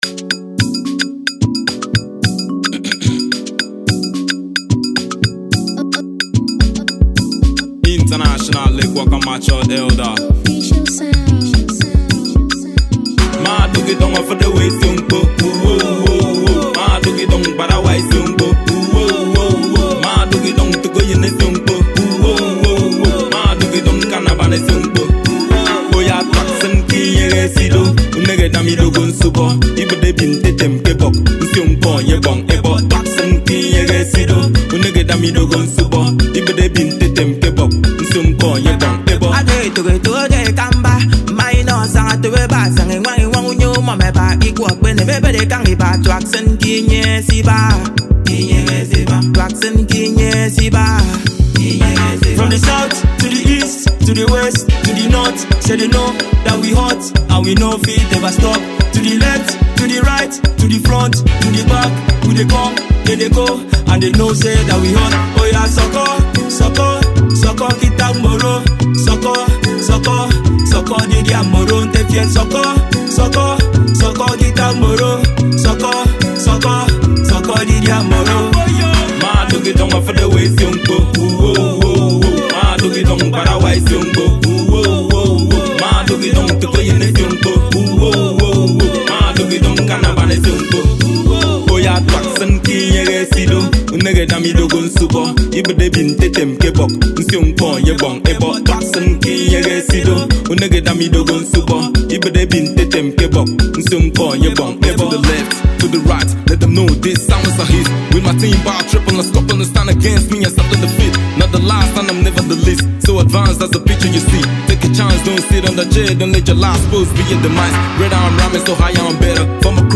Nigerian Rapper, singer, songwriter and talented artist